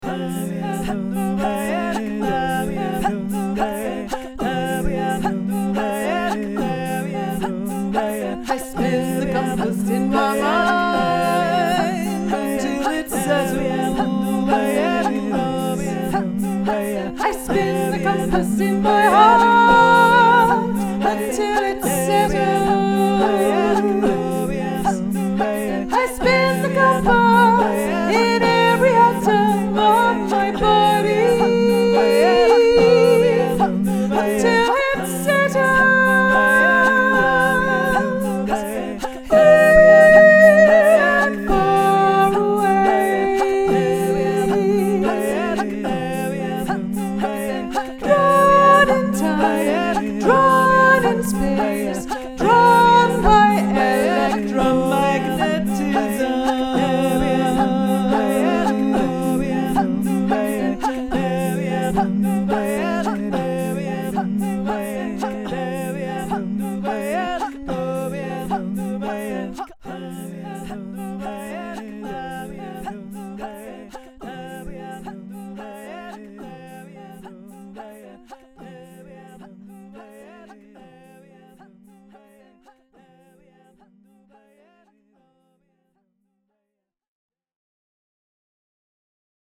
Looping in on the auroral current, a sung story to draw us closer…